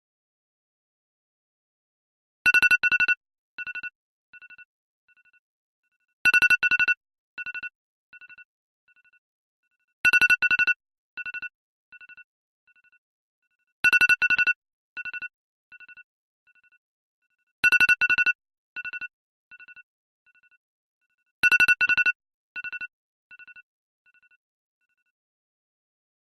timer_effect_aokxuc.mp3